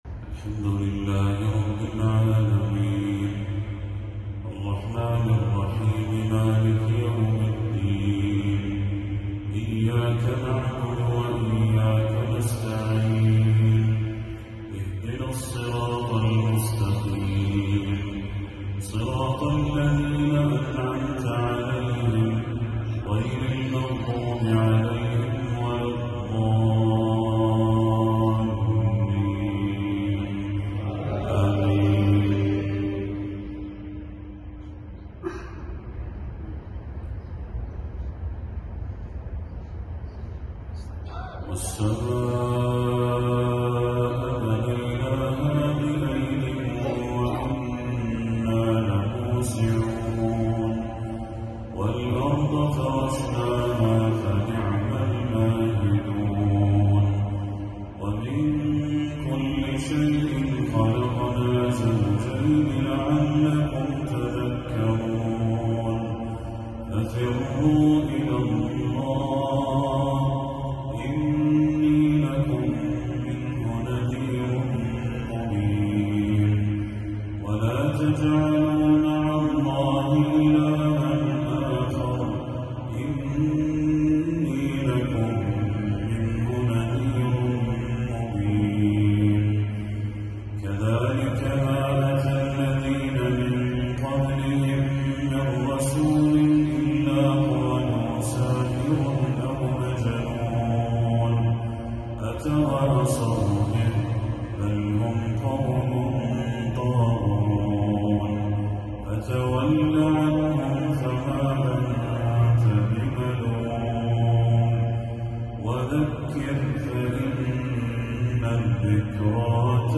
تلاوة لخواتيم سورة الذاريات للشيخ بدر التركي | مغرب 23 صفر 1446هـ > 1446هـ > تلاوات الشيخ بدر التركي > المزيد - تلاوات الحرمين